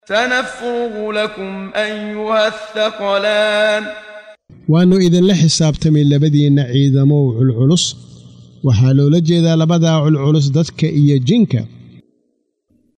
Waa Akhrin Codeed Af Soomaali ah ee Macaanida Suuradda Ar-Raxmaan ( Naxariistaha ) oo u kala Qaybsan Aayado ahaan ayna la Socoto Akhrinta Qaariga Sheekh Muxammad Siddiiq Al-Manshaawi.